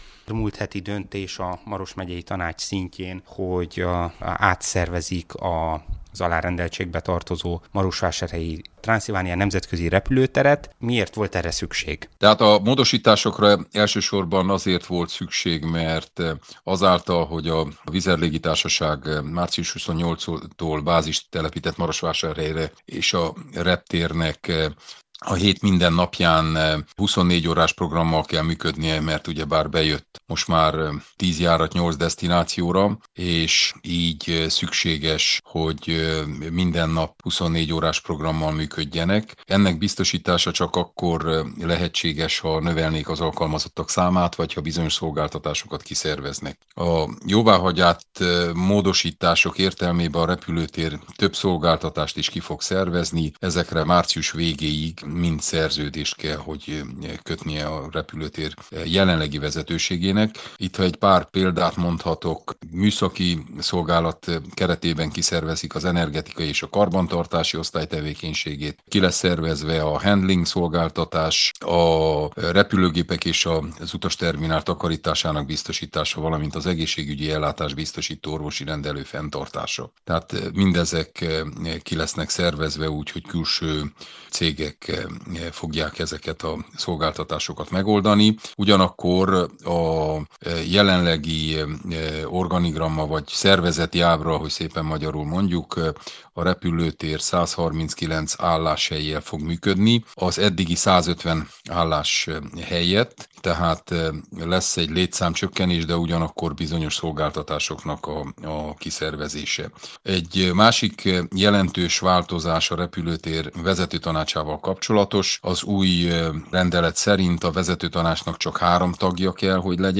Hogy mi indokolta az átalakítást, és milyen hatásai lehetnek a reptér működésére, erről kérdeztük Péter Ferencet, a Maros Megyei Tanács elnökét.